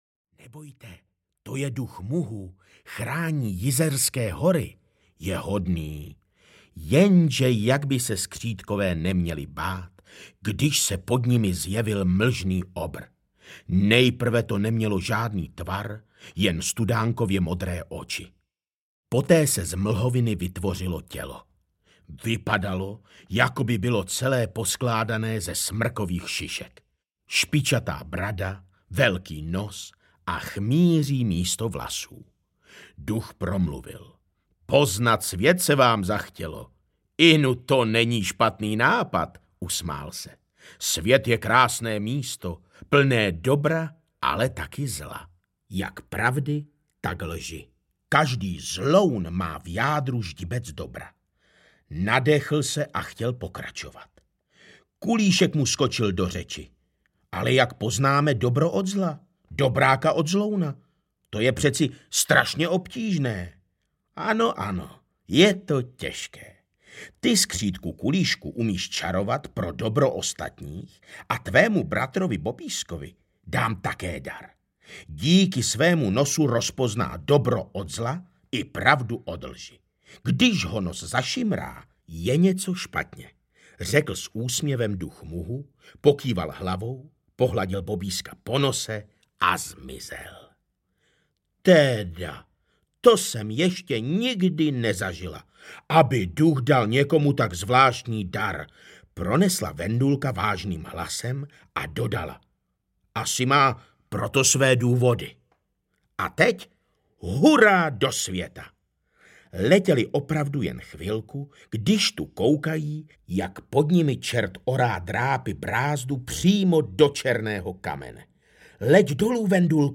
Kulíšek a Bobísek na cestě po Česku audiokniha
Ukázka z knihy